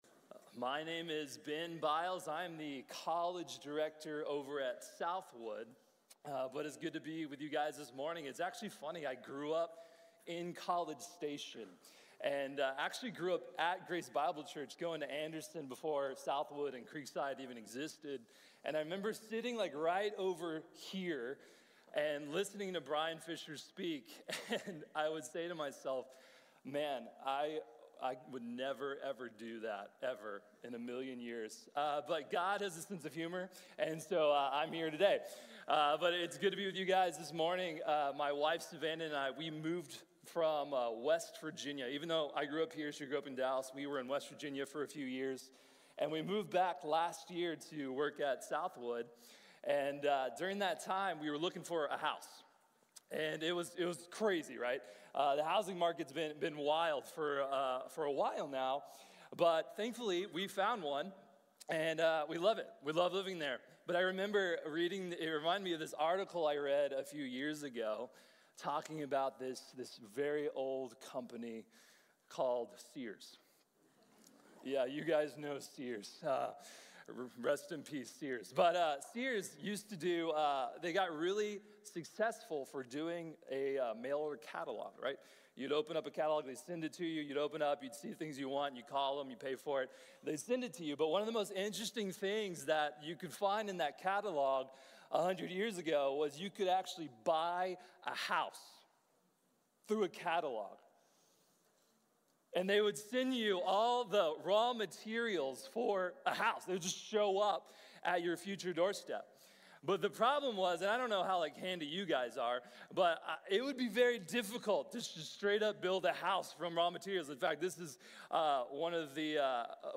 The Trinity | Sermon | Grace Bible Church